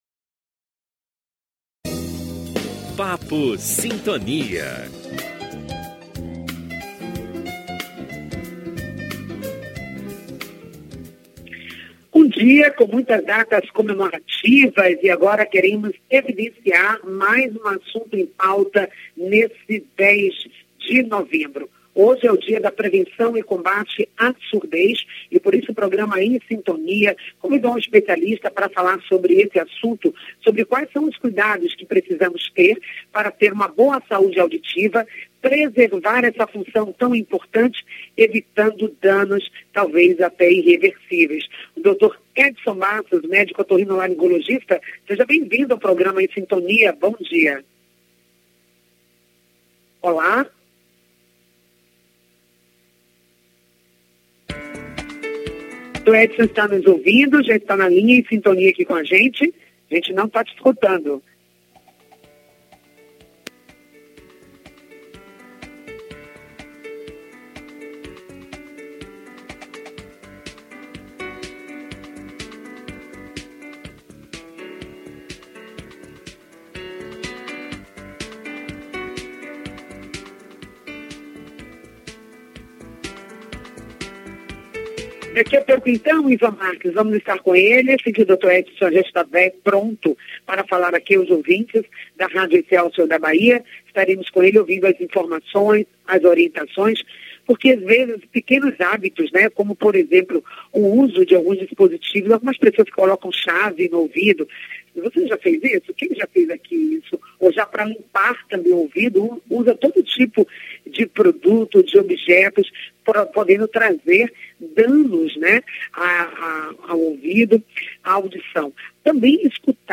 O programa Em Sintonia acontece de Segunda à sexta das 9 às 10h, pela Rádio Excelsior AM 840.